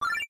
collect_fuel.ogg